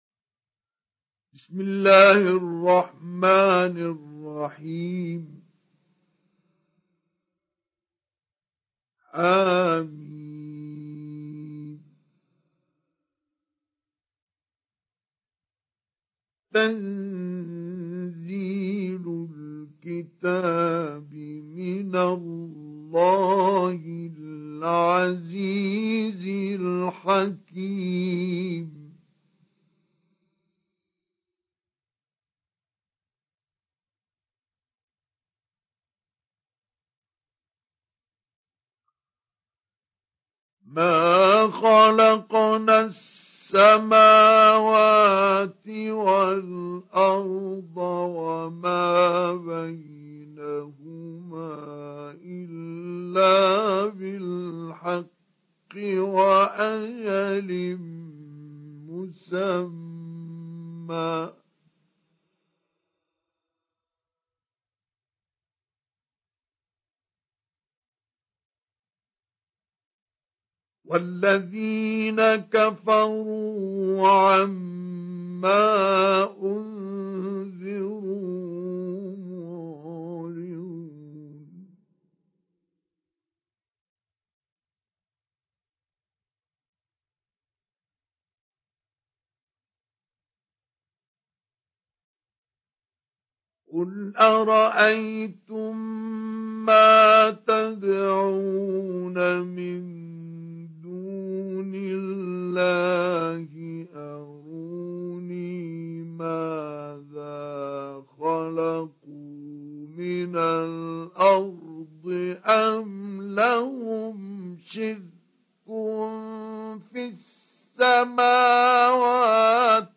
Surat Al Ahqaf Download mp3 Mustafa Ismail Mujawwad Riwayat Hafs dari Asim, Download Quran dan mendengarkan mp3 tautan langsung penuh
Download Surat Al Ahqaf Mustafa Ismail Mujawwad